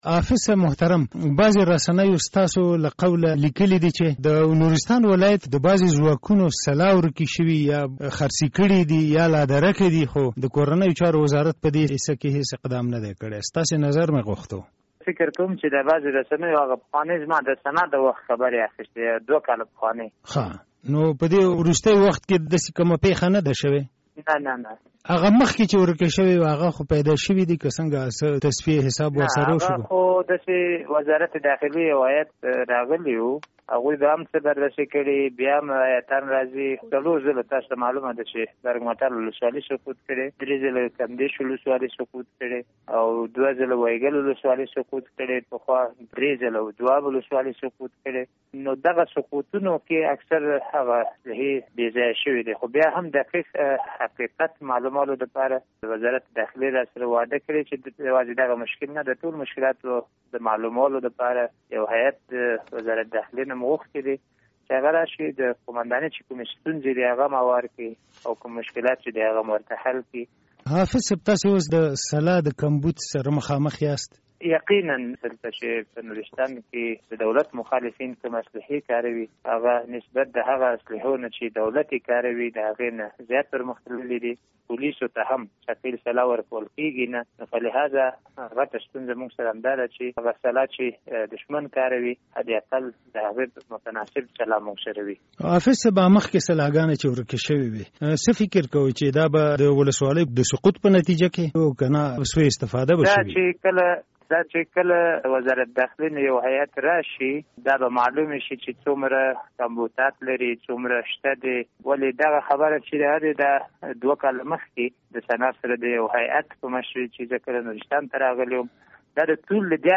د نورستان له والي سره مرکه